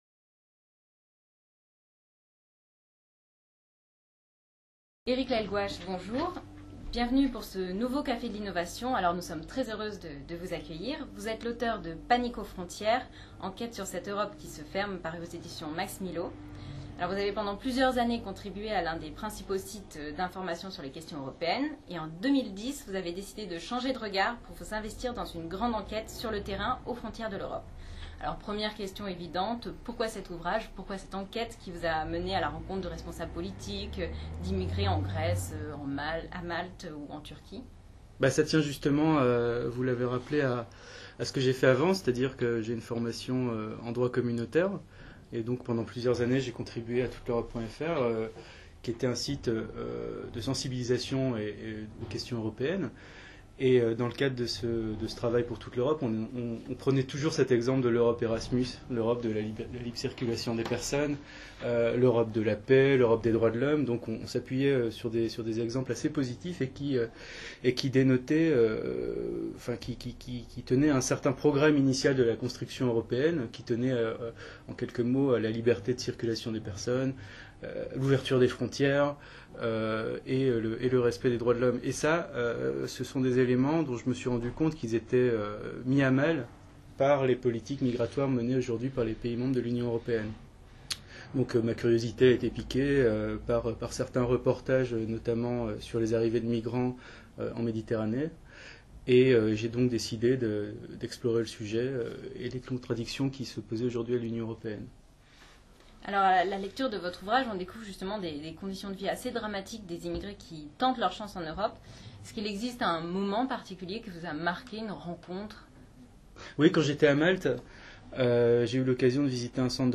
Vous pouvez écouter la conversation ici : 10ème Café de l’innovation politique